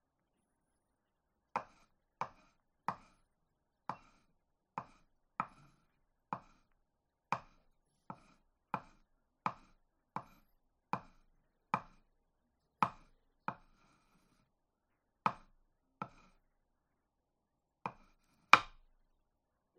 描述：轻功 飞走 起跳
Tag: 轻功 飞走 起跳 古装 武打